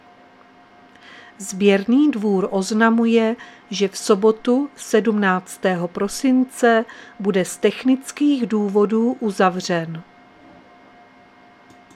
Záznam hlášení místního rozhlasu 16.12.2022